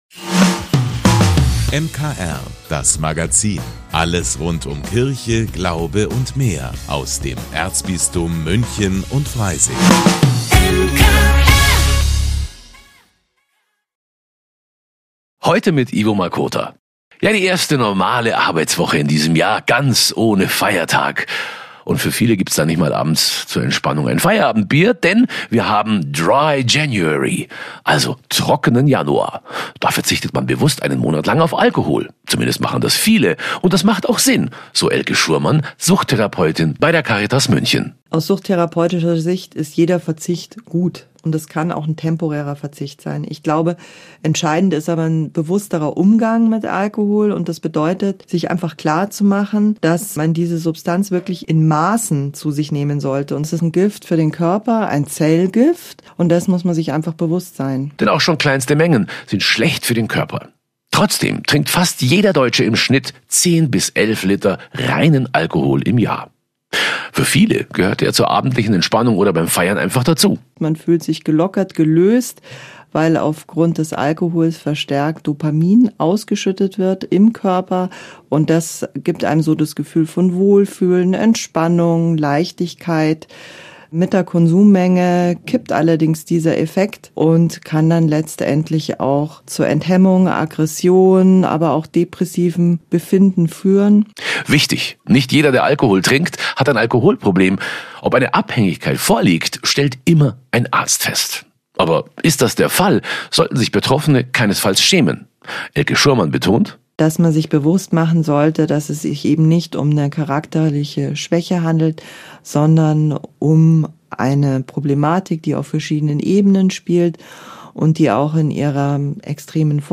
Im Interview mit dem MKR erklärt der 43-Jährige Ordenspriester, warum.